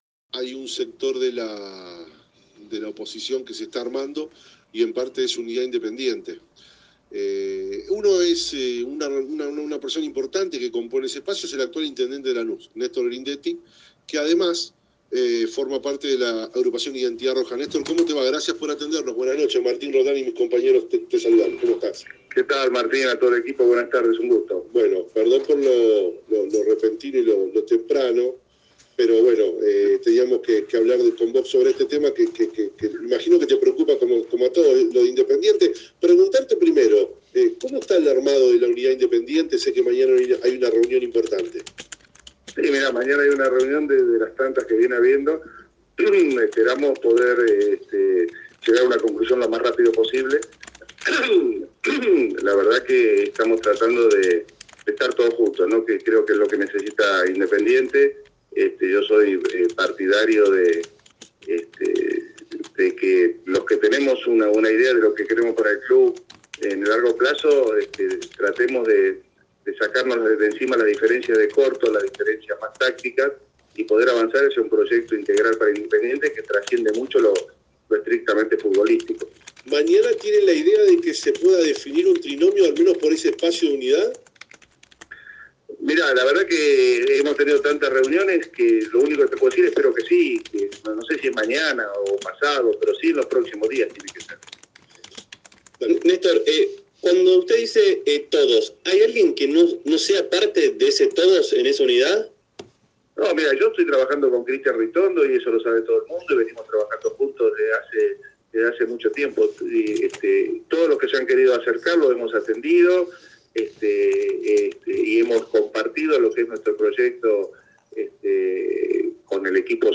Néstor Grindetti intendente de Lanús habló en Rojos de Paasión sobre el armado del frente opositor Unidad Independiente